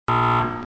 Sonido FX 19 de 42
error.mp3